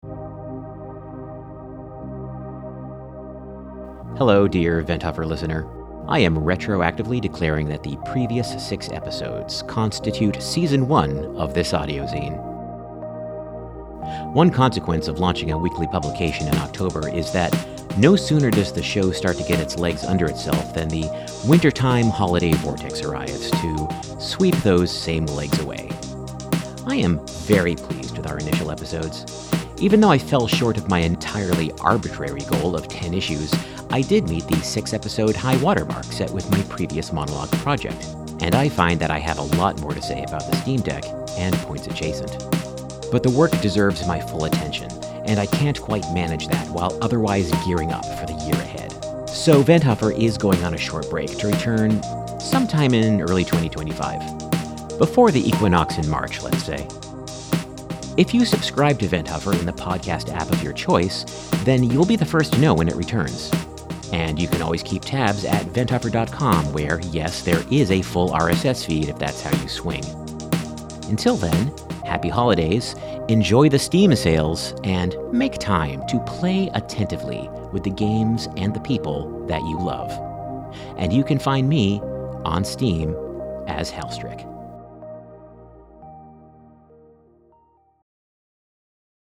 This is an audio zine